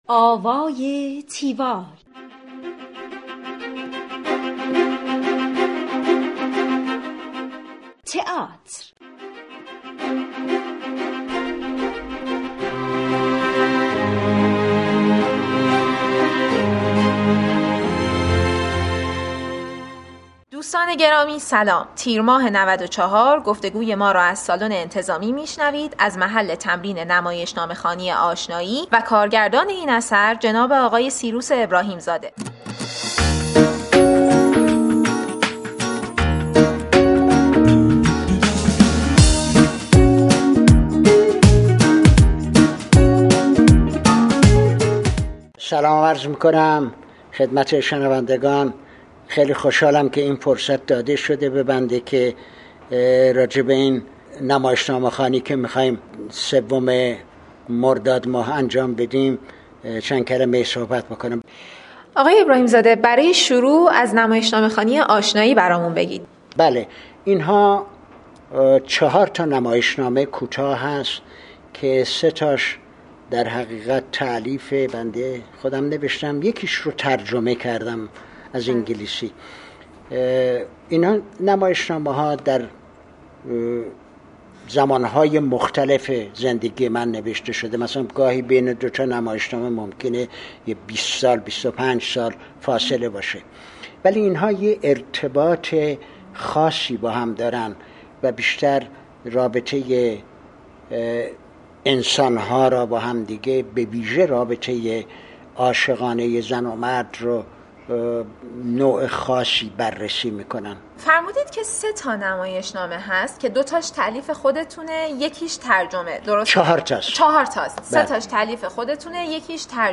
گفتگوی تیوال با سیروس ابراهیم زاده